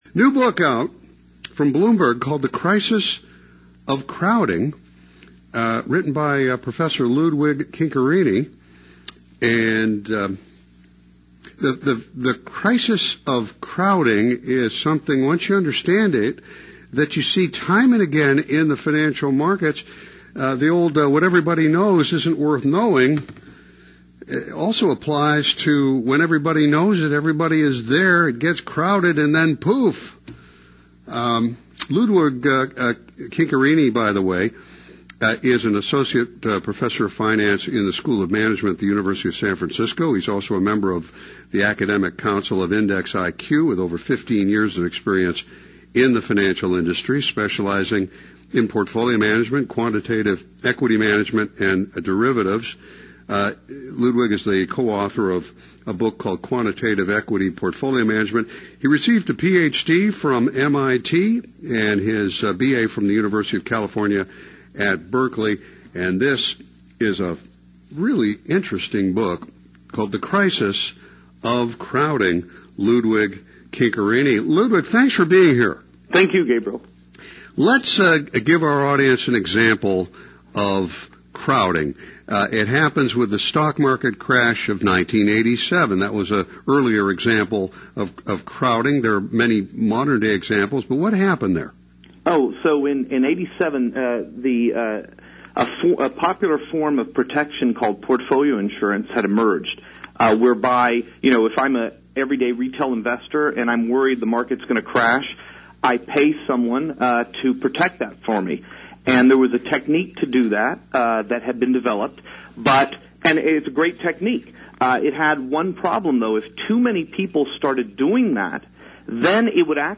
Radio Interview on the Crisis of Crowding